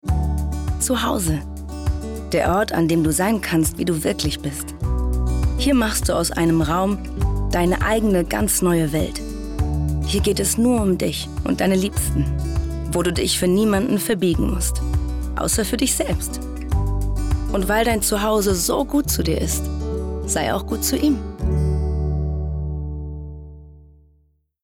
dunkel, sonor, souverän, markant
Mittel minus (25-45)
Norddeutsch
Möbel Werbung
Commercial (Werbung)